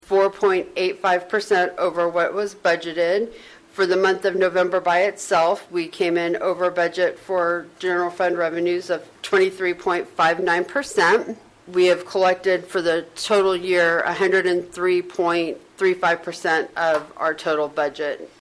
She also talked about the year to date general fund revenues.